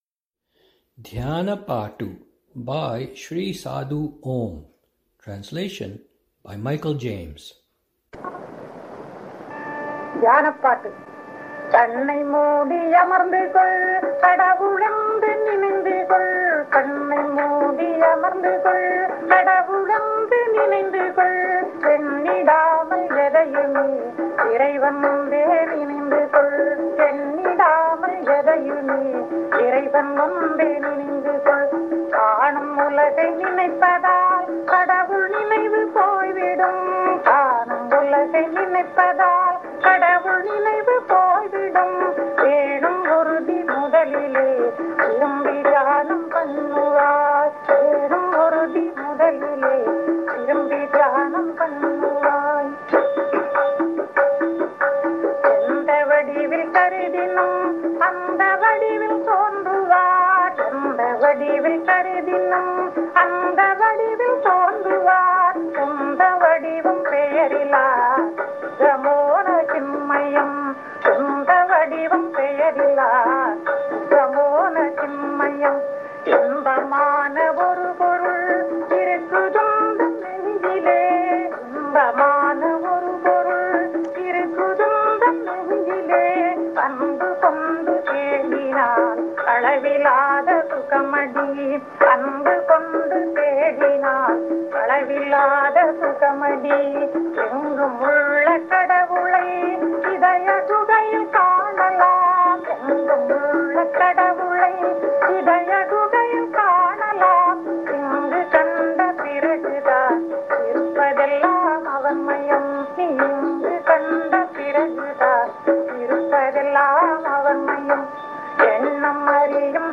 lilting, inspiring rendition